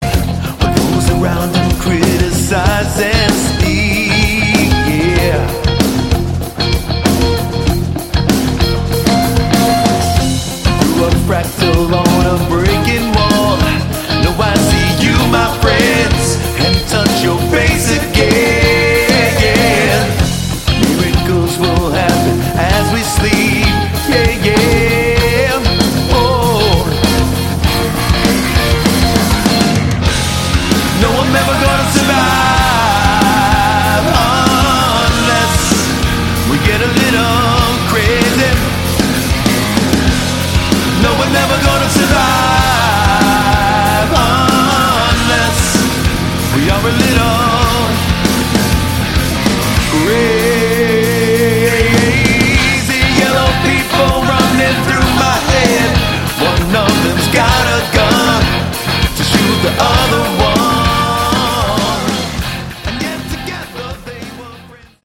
Category: AOR
guitar and vocals
bass and vocals
drums and vocals